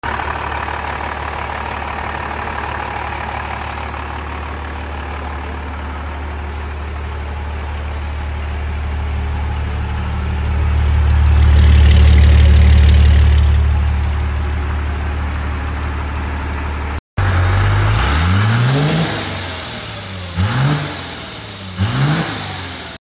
Ole 12v is running again 👌 first start went well